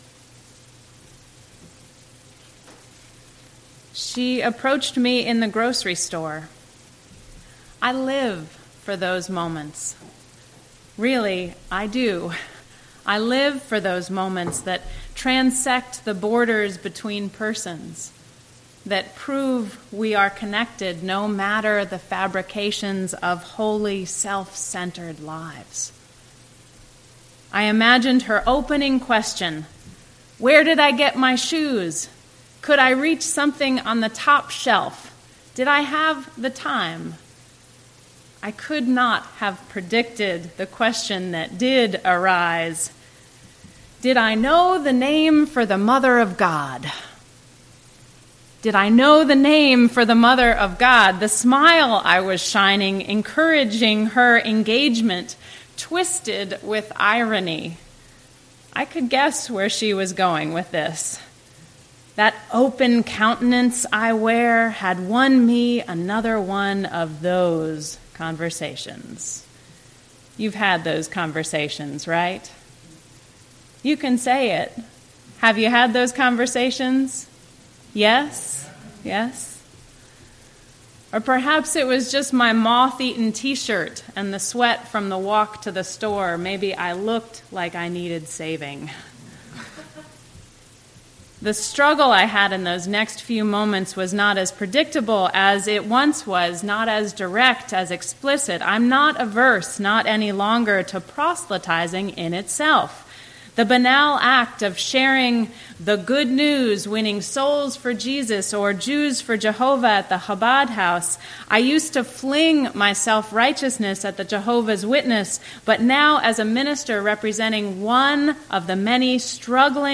This sermon examines the struggle of liberal religious practitioners to advocate for their beliefs with the same conviction found in fundamentalist circles.